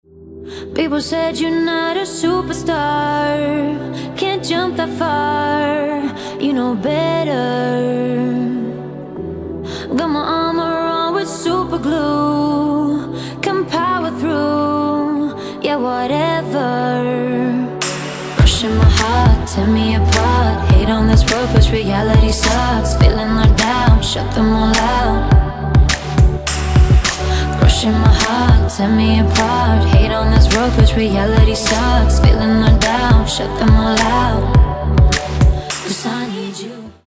поп
женский вокал
dance
vocal